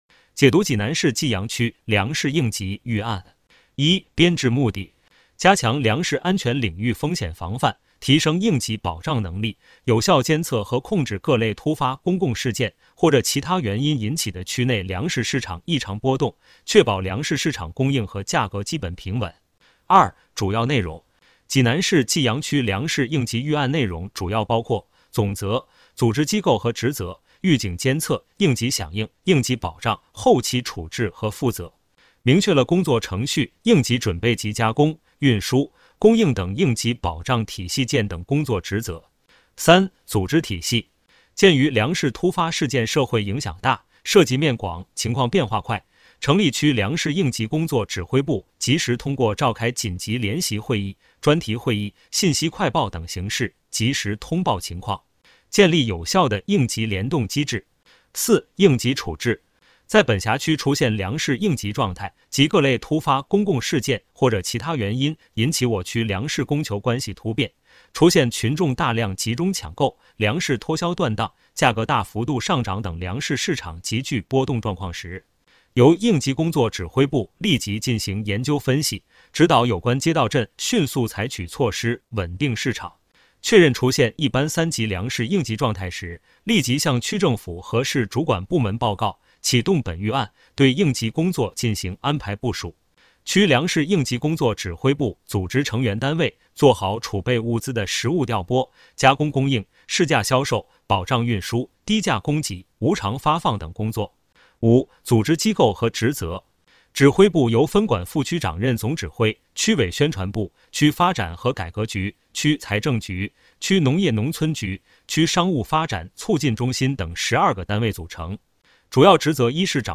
有声朗读